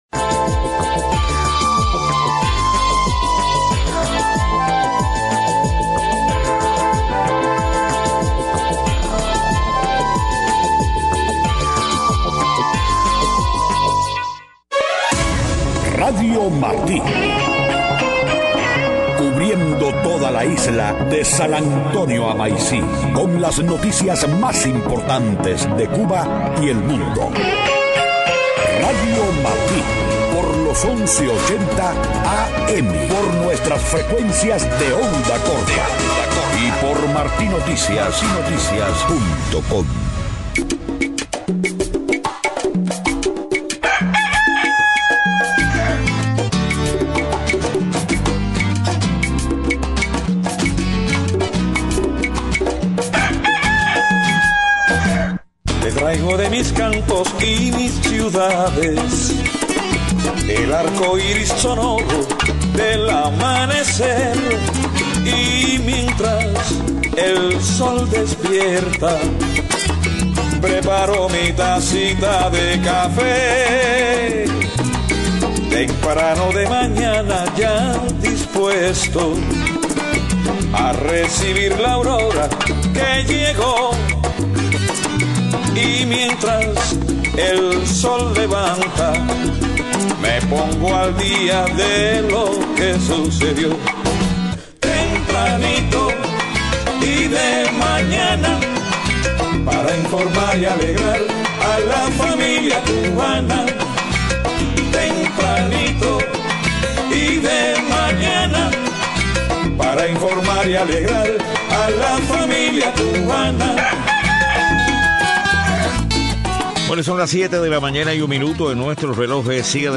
7:00 a.m Noticias: Director de laboratorio en Cuba se disculpa por fabricar perfumes con nombres de Ernesto Guevara y Hugo Chávez. Renuncia presidente del Banco Central de Argentina tras tensiones con la presidenta Cristina Fernández. Canciller chino advierte a EEUU que protestas en Hong Kong son asunto interno de China.